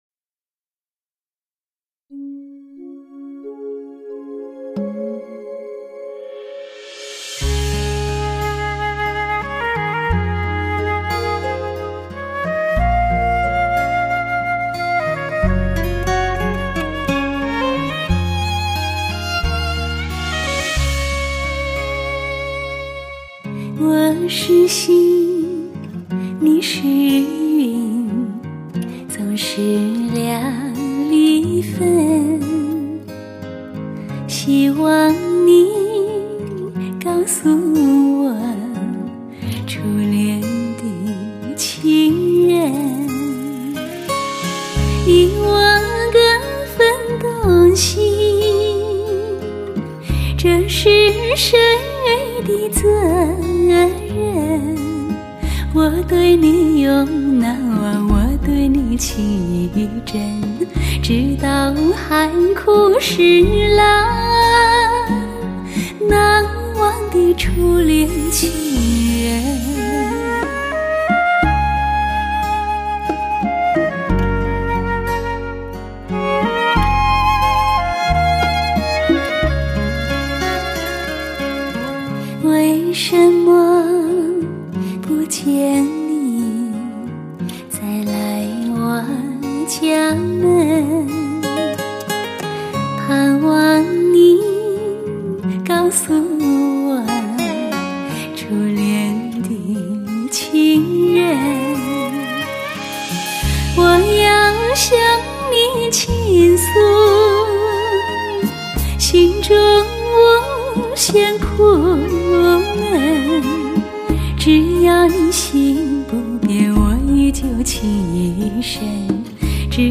华语流行